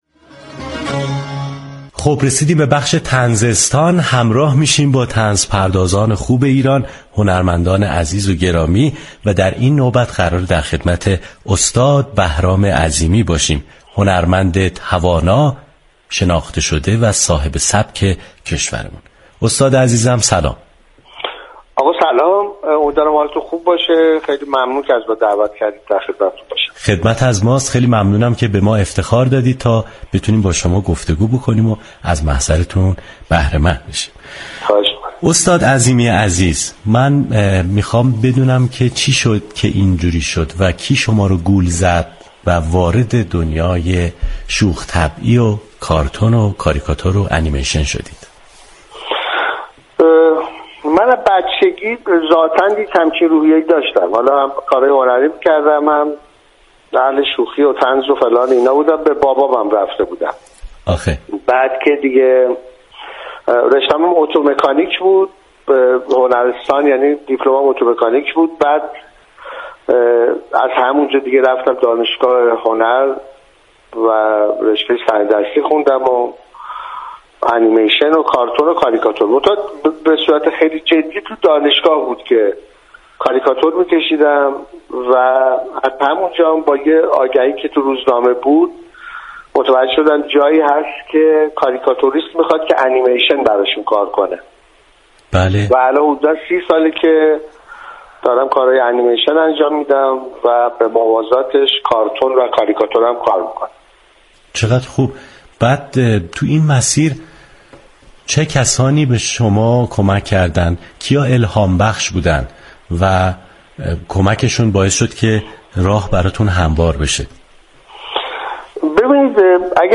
گفتگوی برنامه لیموترش